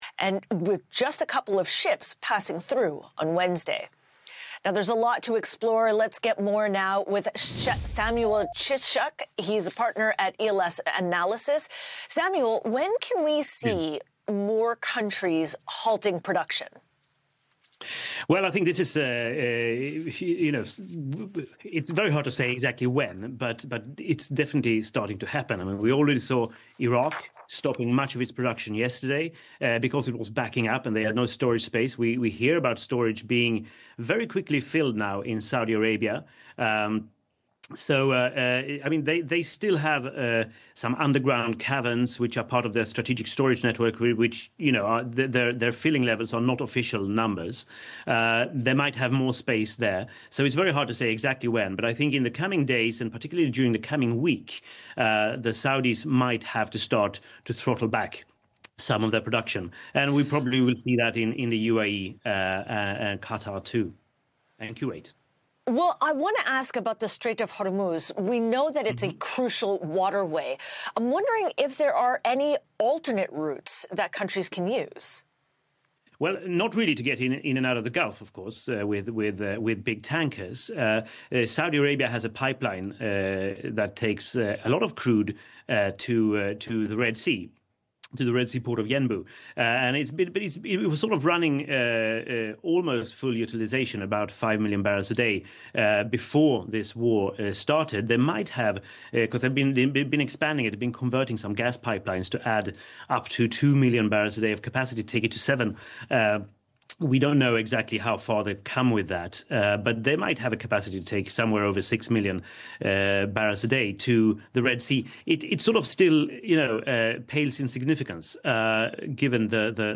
answering on BBC about the current situation in the Middle East and the impact on global oil markets